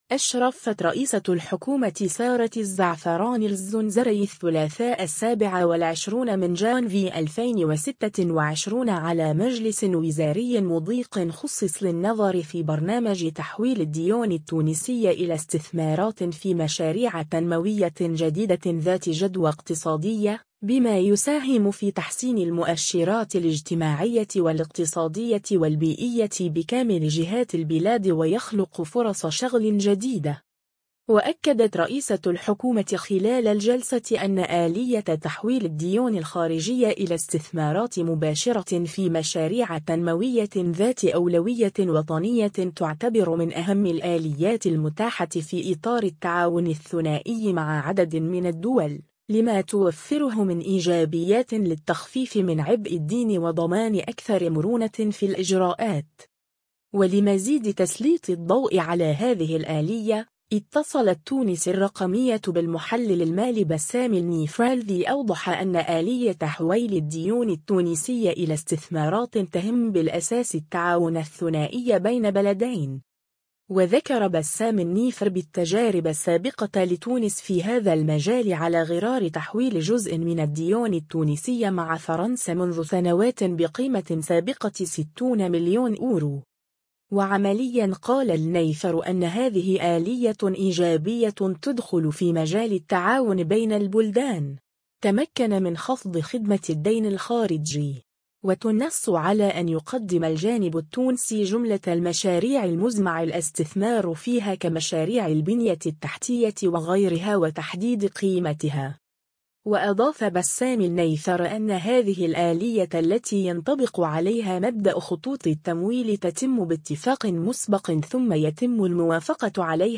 تحويل الديون التونسية إلى استثمارات آلية إيجابية وتمكن من خفض خدمة الدين الخارجي (تصريح)